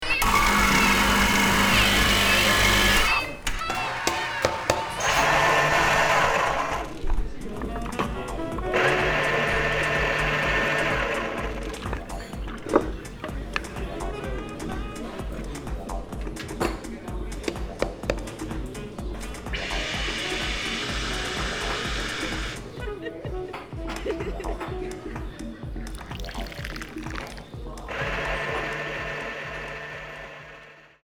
Otrevligt, stickande och jobbigt taget ur sitt sammanhang. Men vad händer när vi adderar lager efter lager som förklarar vad det första ljudet var?